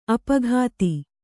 ♪ apaghāti